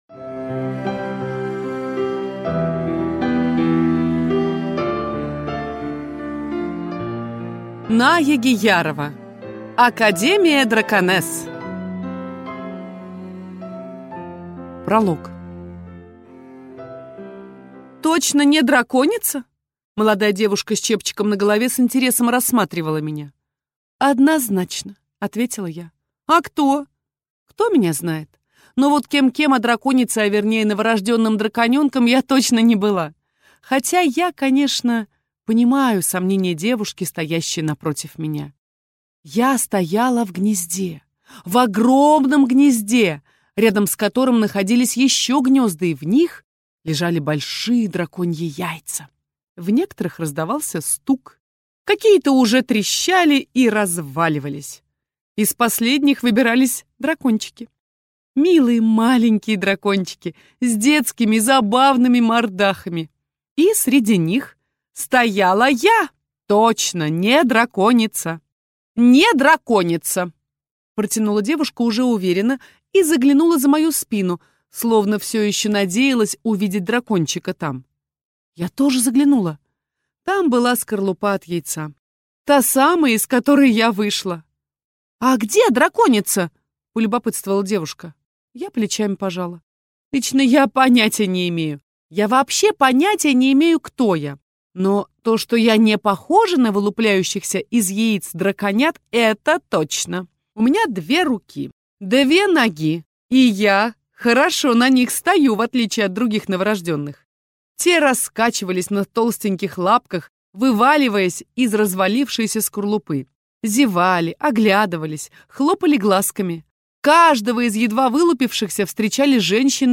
Аудиокнига Академия Драконесс | Библиотека аудиокниг